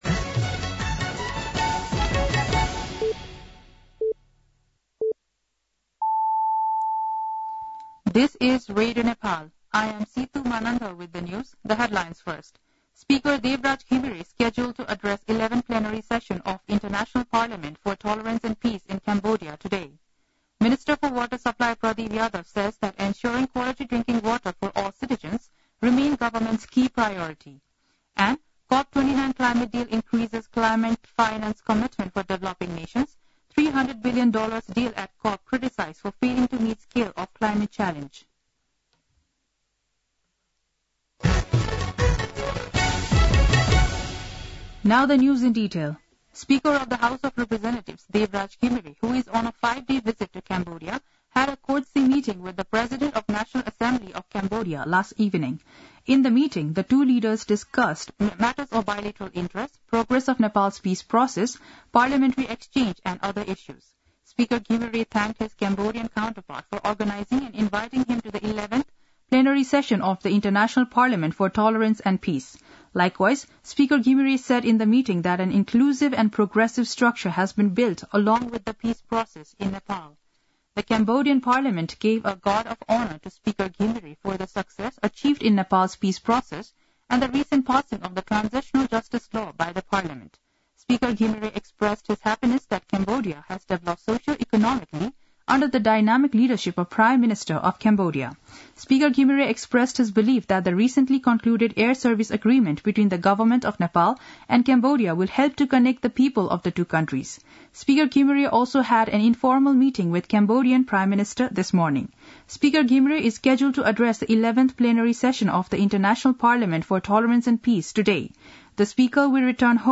दिउँसो २ बजेको अङ्ग्रेजी समाचार : १० मंसिर , २०८१
2-pm-English-News-4.mp3